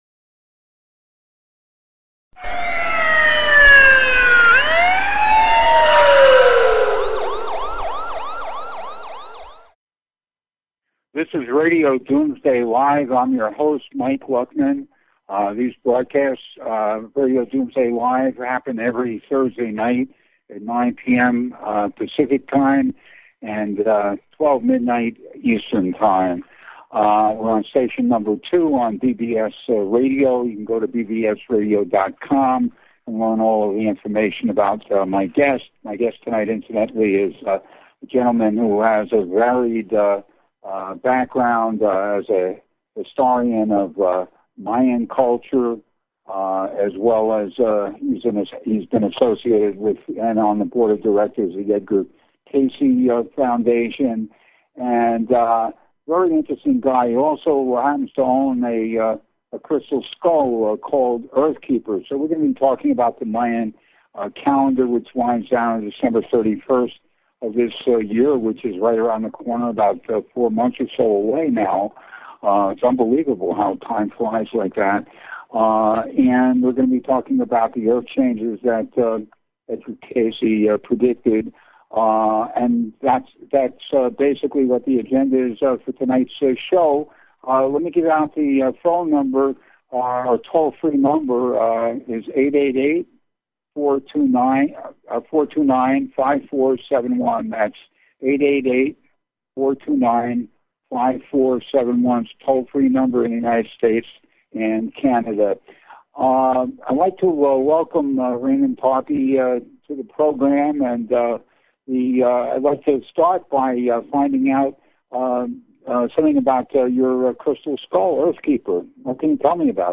Talk Show Episode, Audio Podcast, Starship_One_Radio and Courtesy of BBS Radio on , show guests , about , categorized as